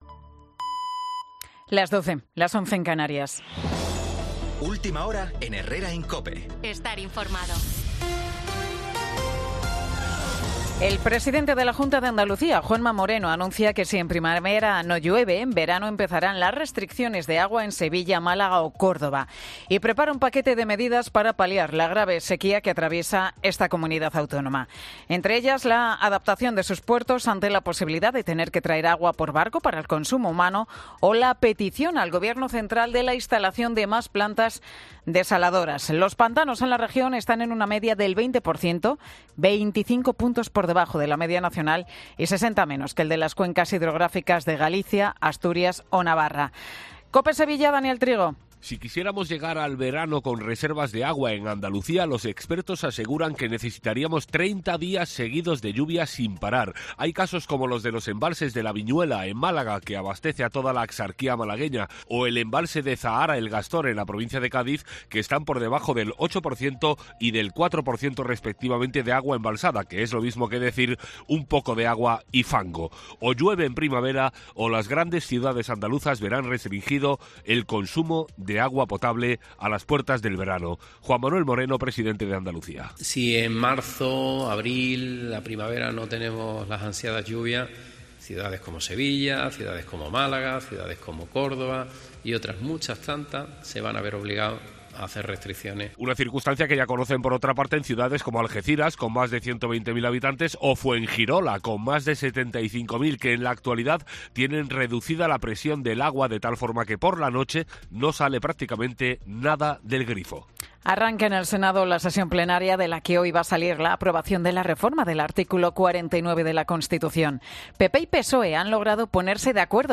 Boletín de Noticias de COPE del 17 de enero del 2024 a las 12 horas